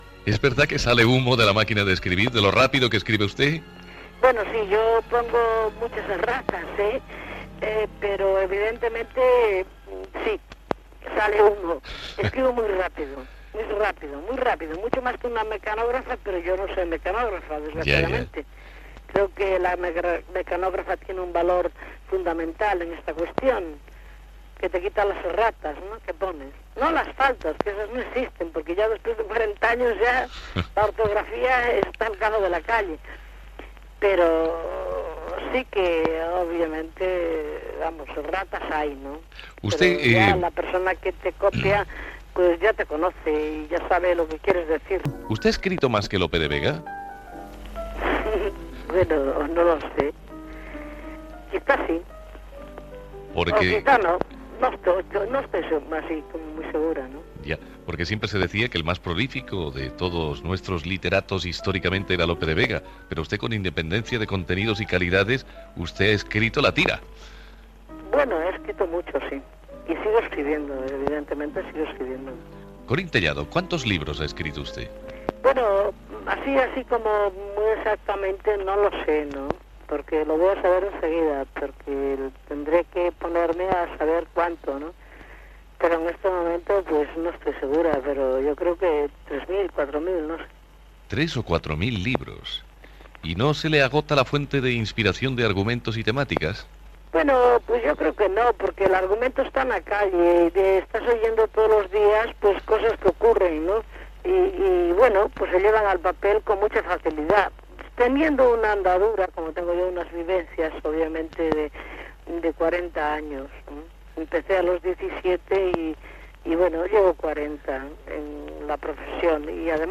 Entrevista a l'escriptora Corín Tellado